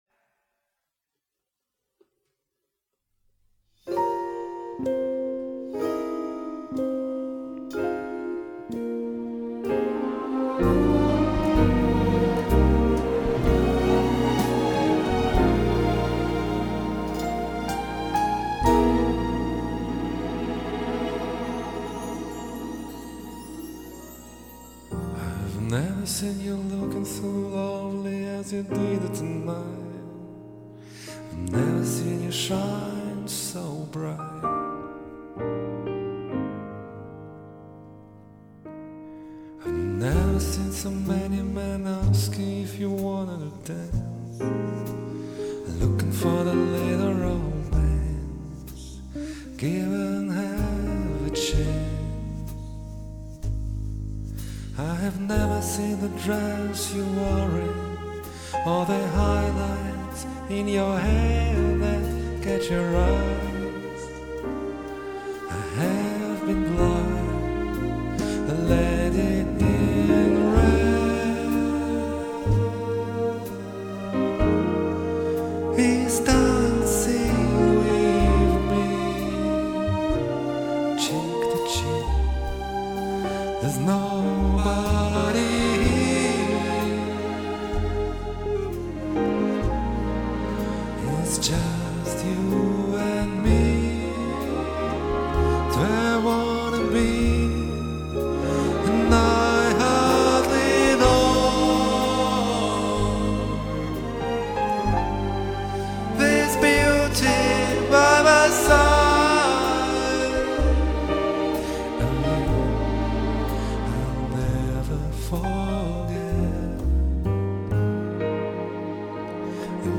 Комментарий соперника: Блюз на блюз )
Вы угадали. на B-Track